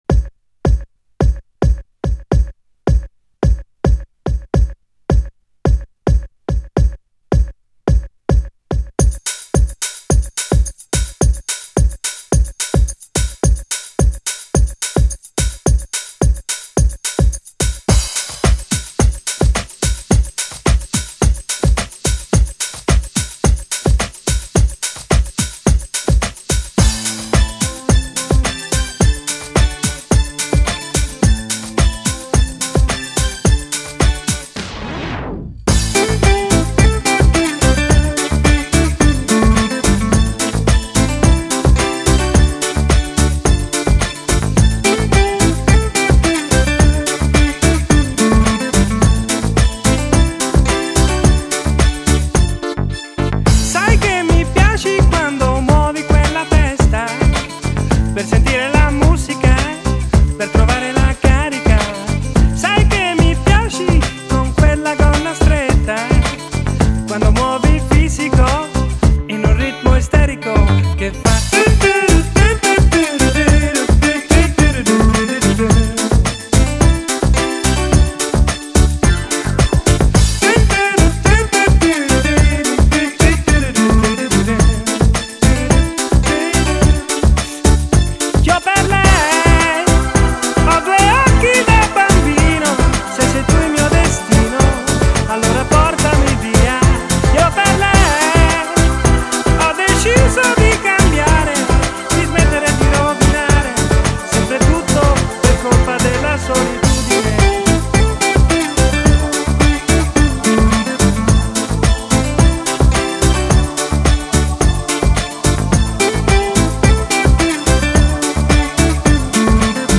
Genre: Dance.